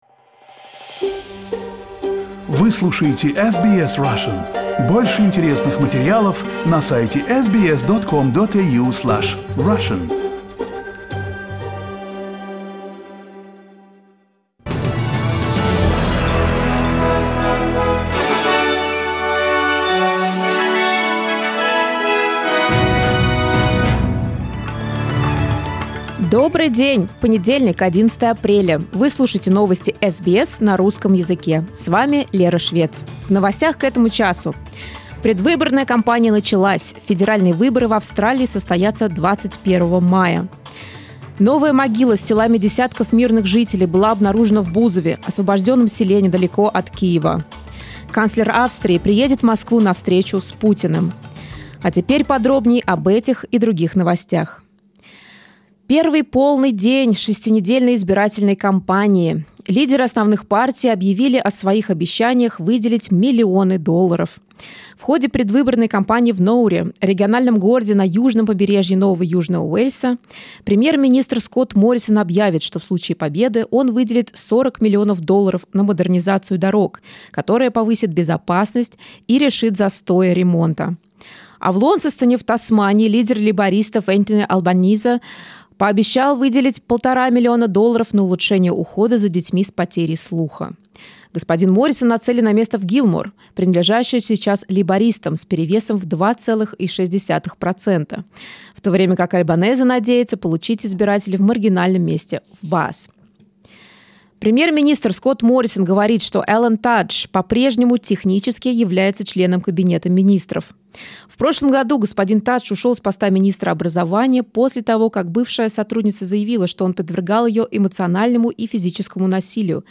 SBS news in Russian — 11.04